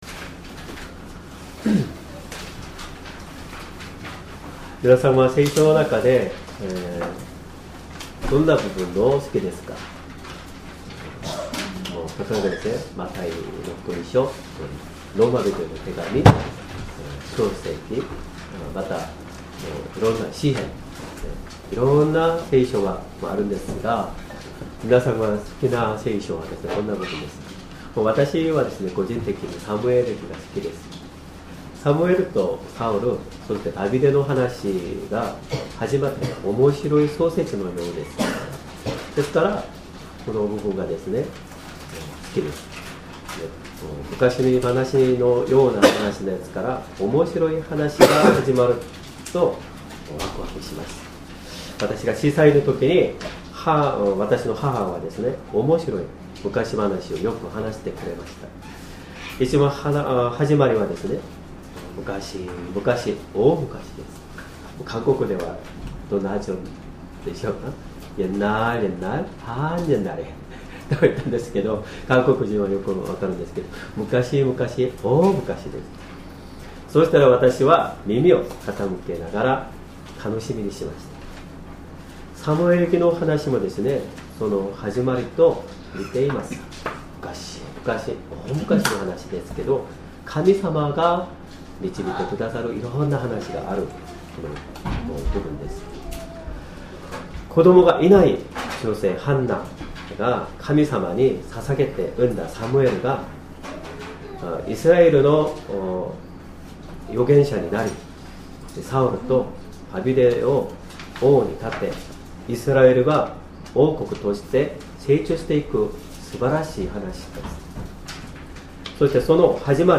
Sermon
2025年 1月5日 主日礼拝 説教 「主があなたを呼ばれたら」 聖書 Ⅰサムエル記３章１～１０節 3:1 さて、少年サムエルはエリのもとで【主】に仕えていた。